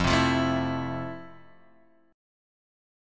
F Major 7th